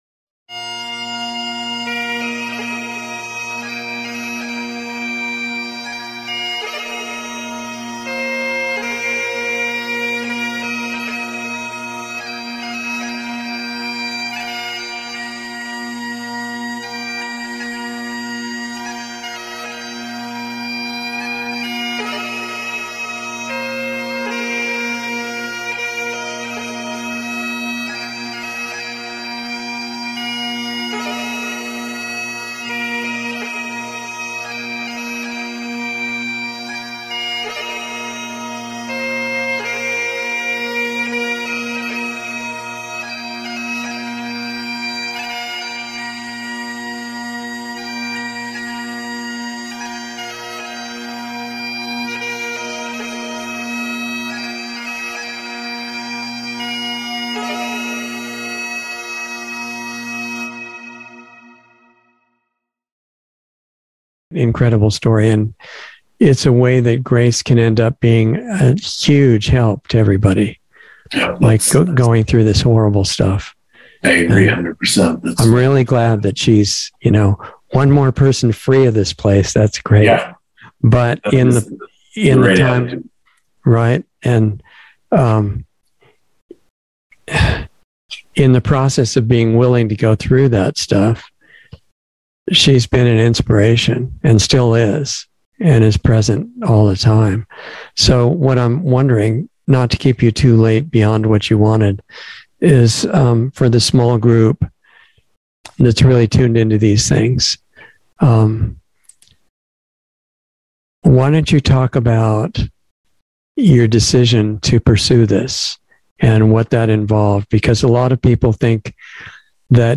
Insider Interview 6/22/22